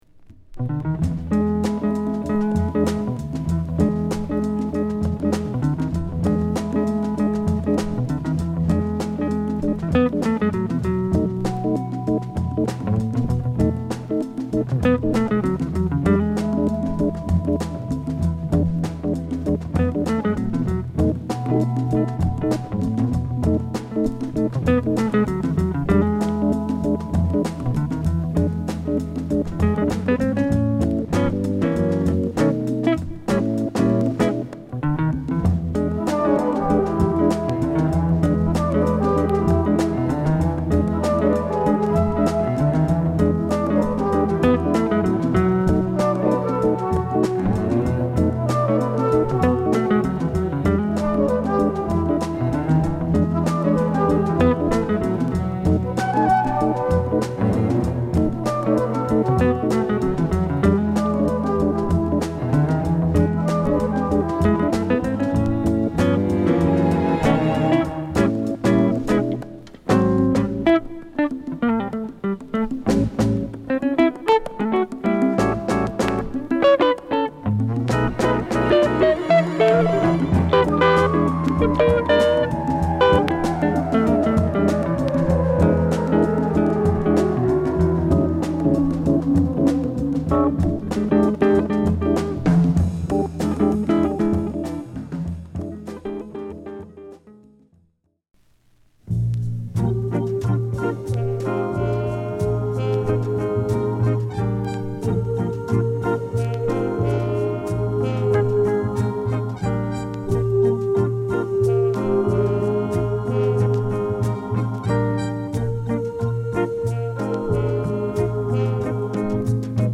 ＊スレ傷が多少あり/試聴ファイルをご確認下さい。